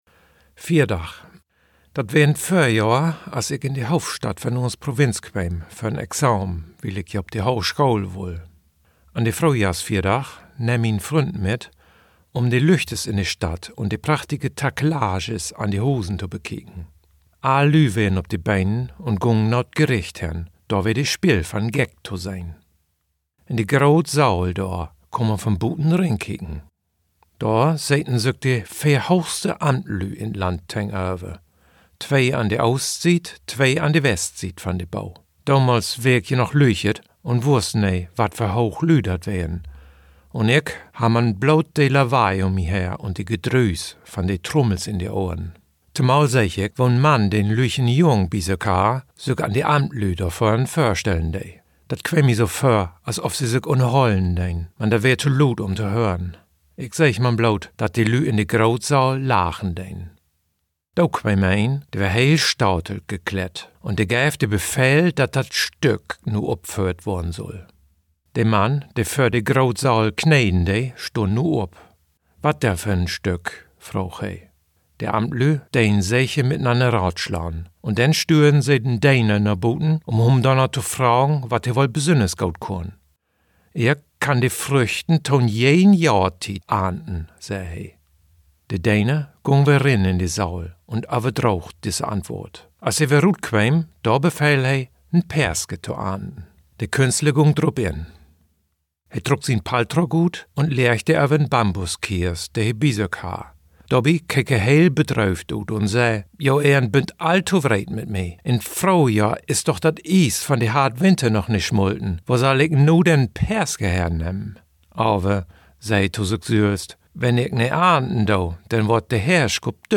Plattdüsk, Plattdeutsch, Ostfreesk, Ostfriesisch